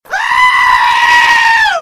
sheep.mp3